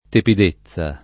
tiepidezza [tLepid%ZZa] o tepidezza [